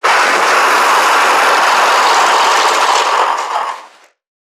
NPC_Creatures_Vocalisations_Infected [1].wav